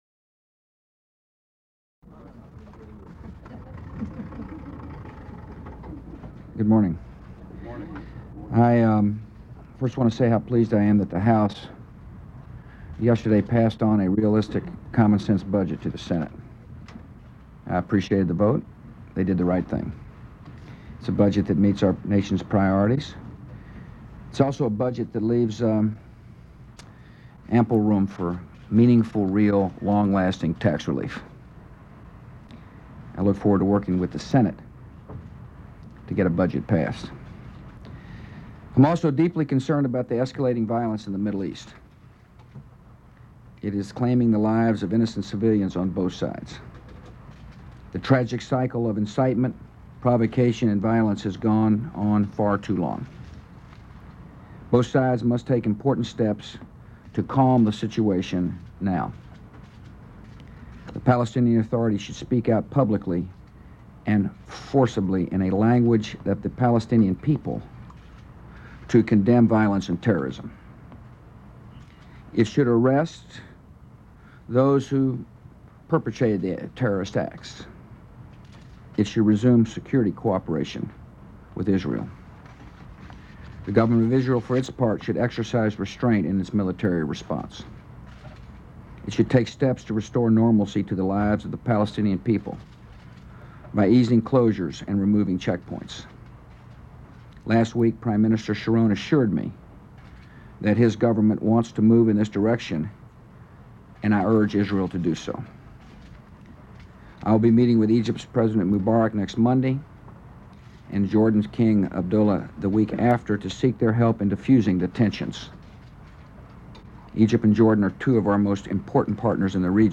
U.S. President George W. Bush talks to the press on various topics